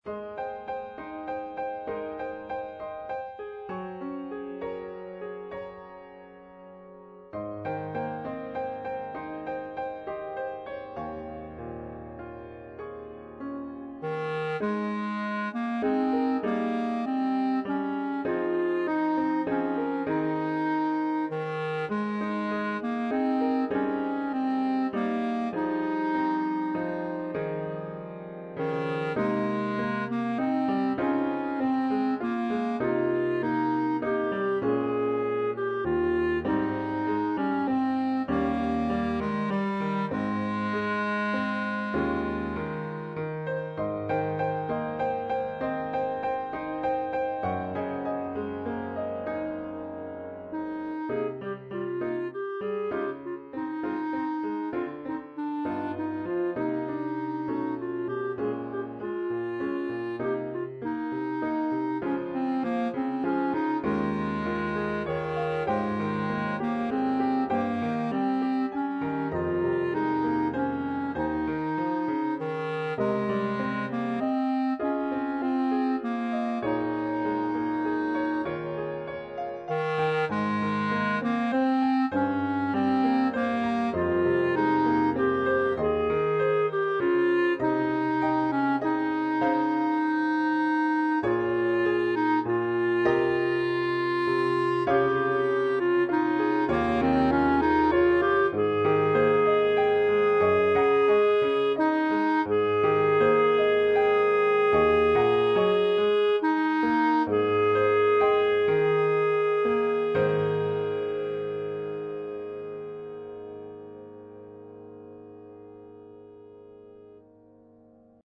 Style: Irish feel in 3
Instrumentation: Clarinet and Piano